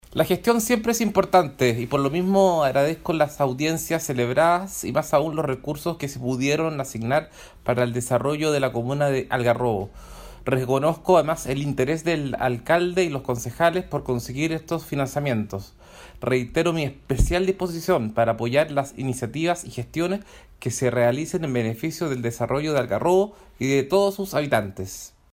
(Te invitamos a escuchar las palabras del Diputado Andrés Celis sobre la obtención de éstos